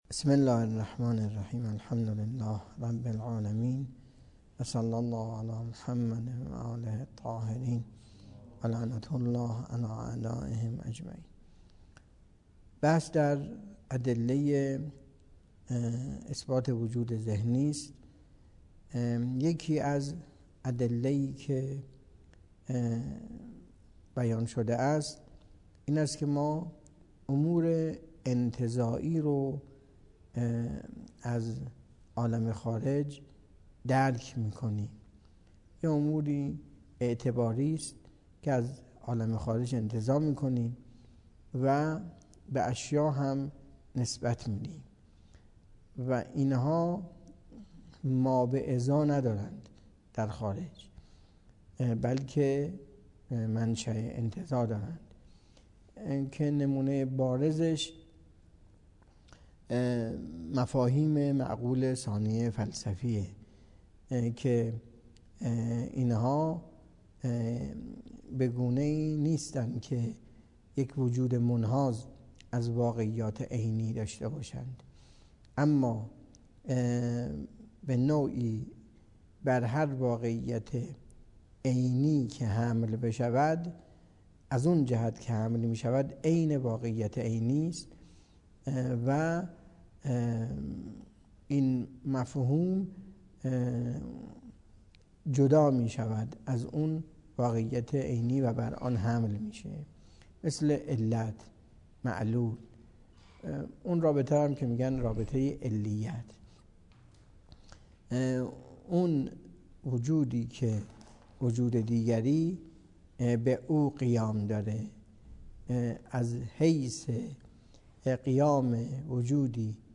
درس فلسفه اسفار اربعه